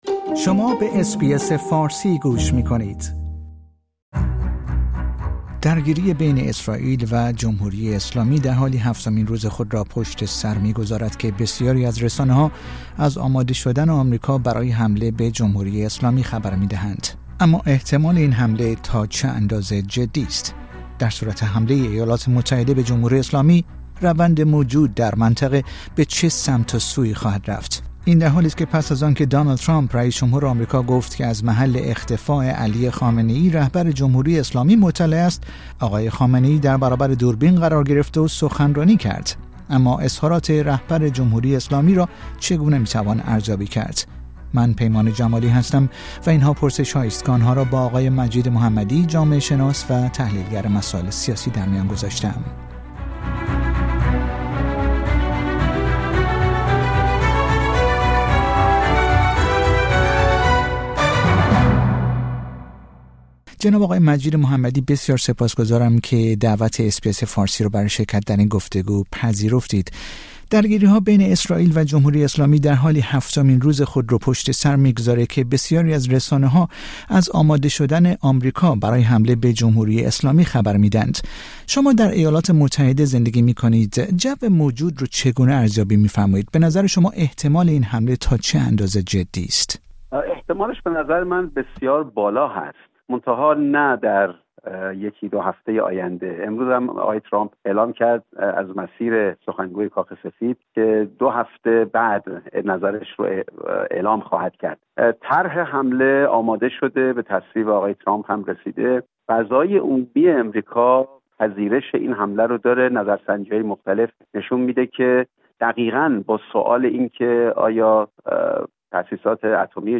نظرات ارائه شده در این گفتگو نظرات کارشناس مربوطه است و نشانگر دیدگاه های اس بی اس فارسی نیست.